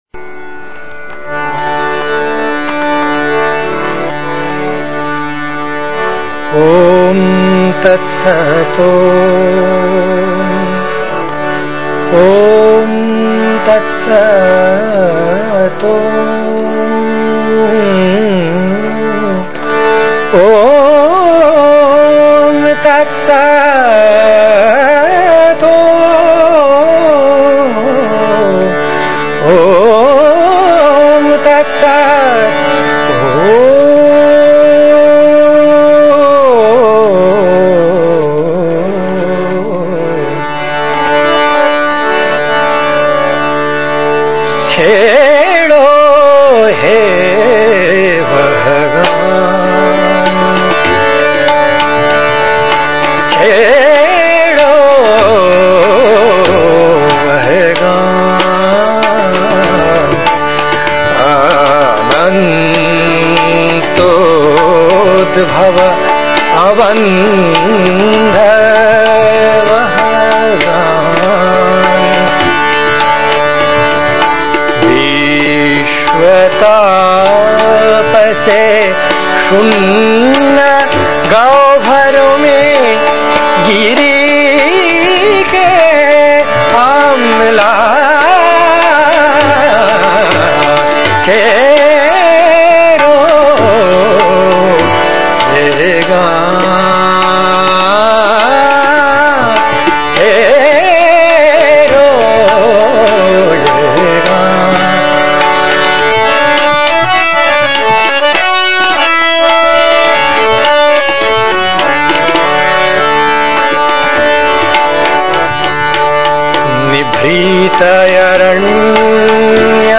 hindi-song-of-sannyasin3.mp3